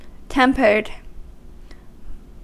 Ääntäminen
Vaihtoehtoiset kirjoitusmuodot (vanhentunut) temper'd Ääntäminen US Haettu sana löytyi näillä lähdekielillä: englanti Tempered on sanan temper partisiipin perfekti.